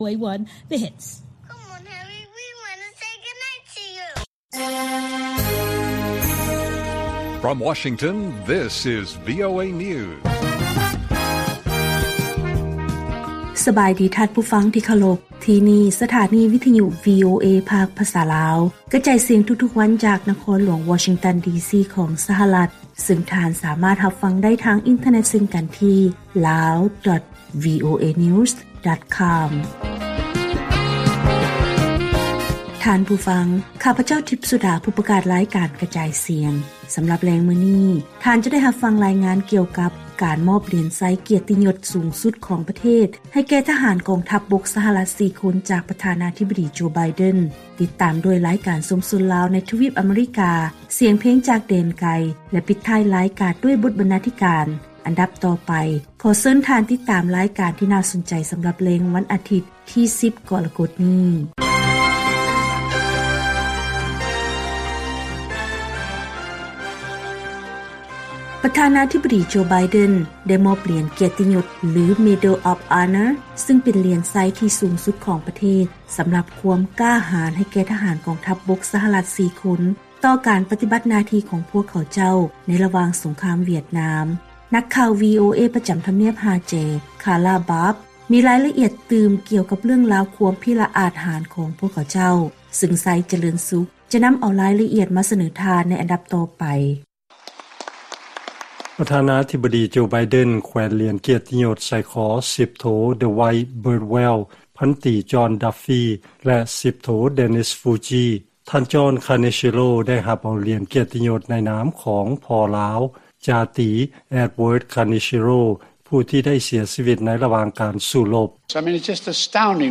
ວີໂອເອພາກພາສາລາວ ກະຈາຍສຽງທຸກໆວັນ ສຳລັບແລງມື້ນີ້ ເຮົາມີ: 1.